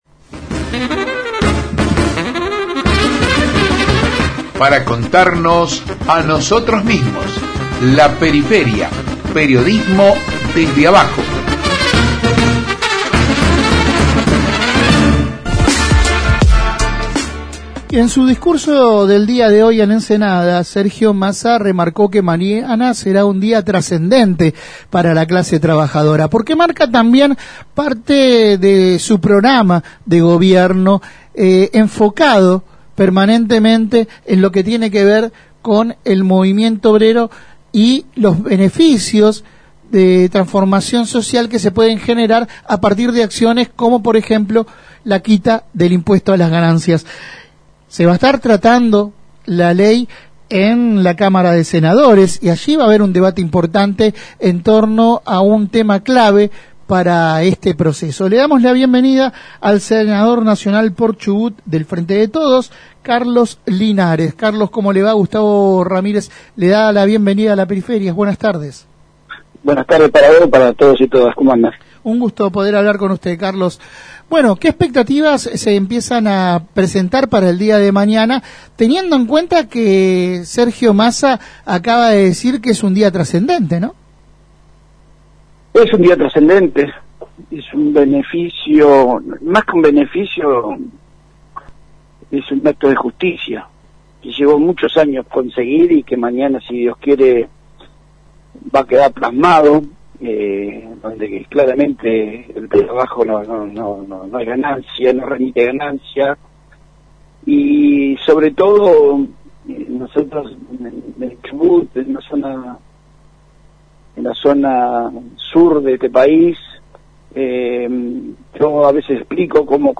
El senador por Chubut del Frente de Todos, Carlos Linares aseguró en La Periferia que la jornada del jueves será una jornada histórica para las y los trabajadores. Al mismo tiempo confirmó que el bloque oficialista tiene los senadores para dar cuorum.
Compartimos la entrevista completa: